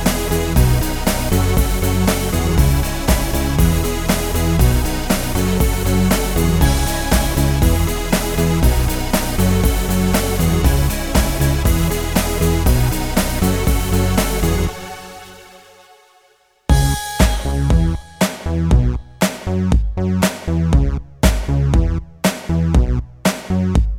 For Solo Female Pop (2010s) 4:01 Buy £1.50